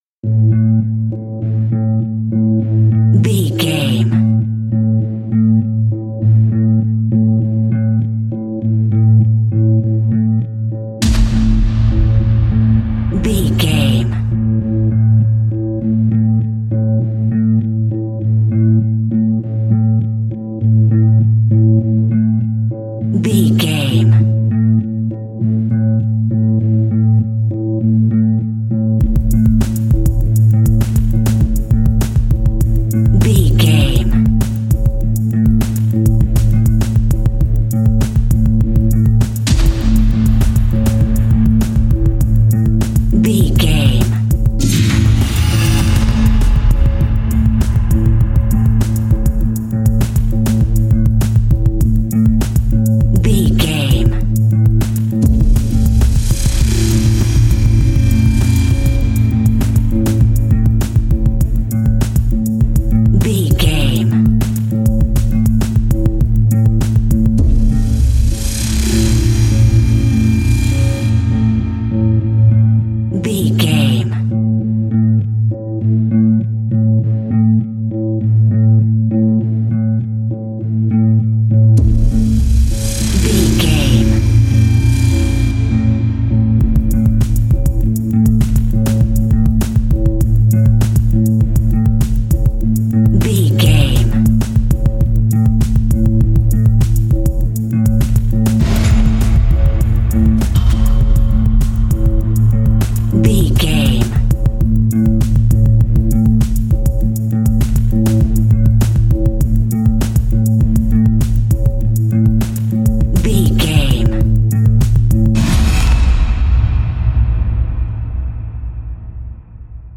Thriller
Aeolian/Minor
bass guitar
synthesiser
piano
drum machine
tension
ominous
dark
suspense
mysterious
haunting
creepy